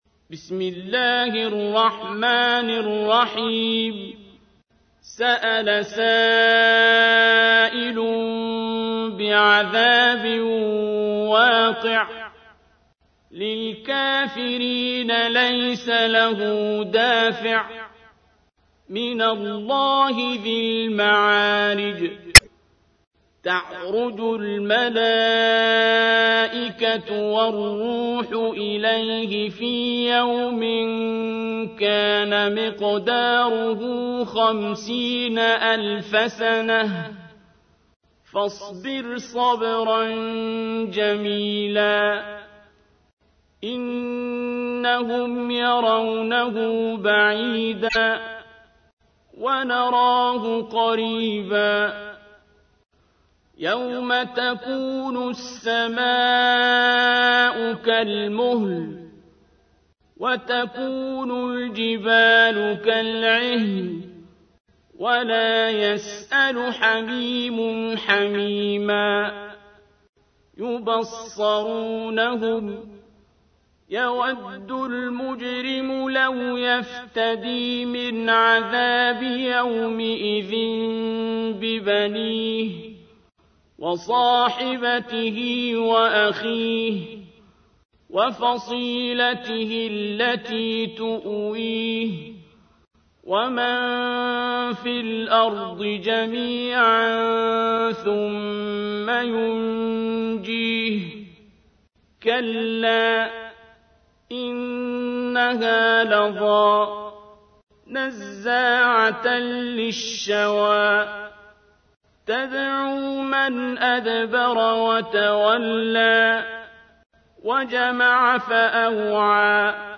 تحميل : 70. سورة المعارج / القارئ عبد الباسط عبد الصمد / القرآن الكريم / موقع يا حسين